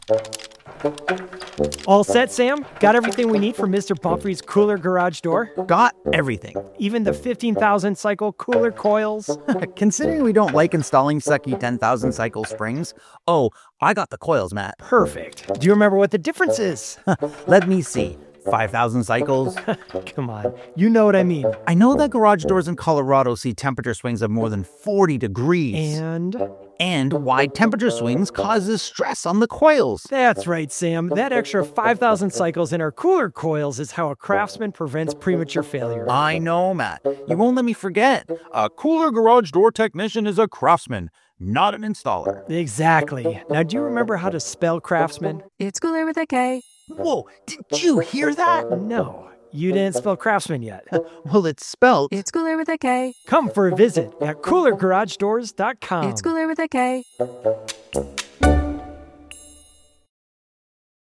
Radio ads
Production, sound design, and mixing for radio spots across multiple clients.
Fig. 01 — Kooler Koils, final mix Production / Contrast Logic
Multiple spots. Multiple clients. All broadcast-ready.